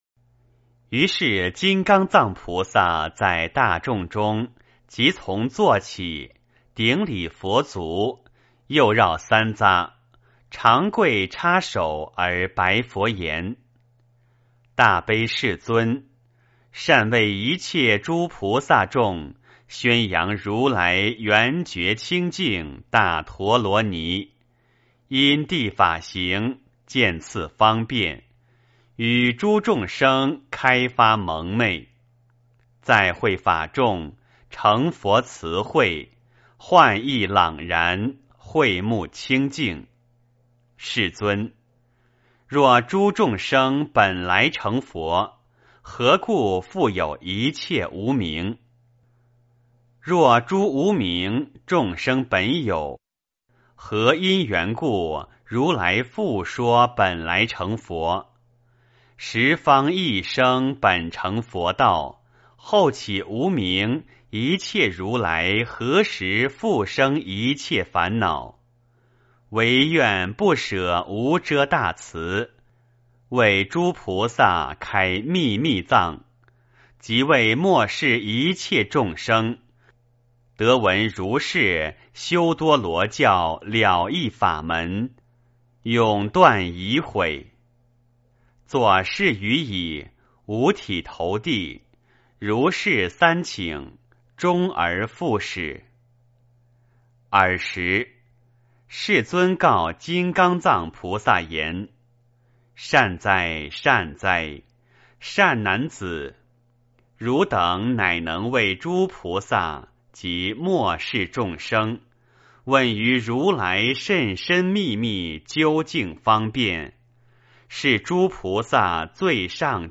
圆觉经-04金刚藏菩萨 - 诵经 - 云佛论坛